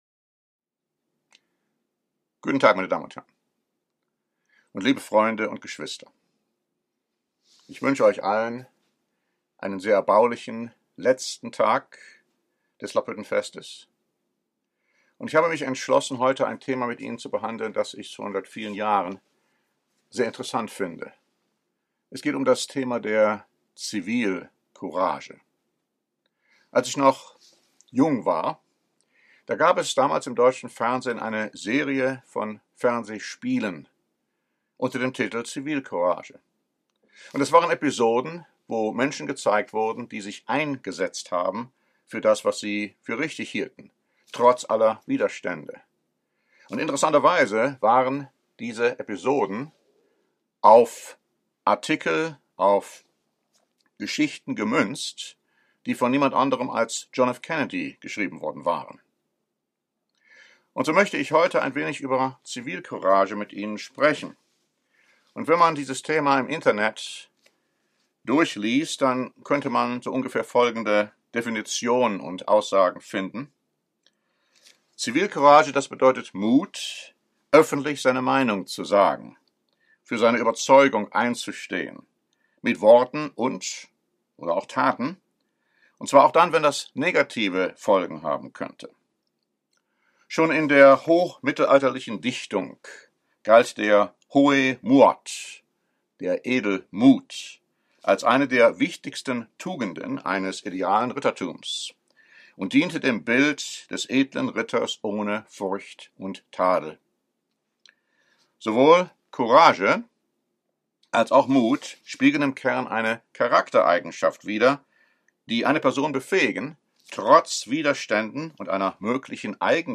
Diese Predigt bespricht viele Beispiele von gerechten Menschen, die unter Druck Zivilcourage dokumentierten und für ihre Überzeugung, die Wahrheit und Gottes Wort eintraten.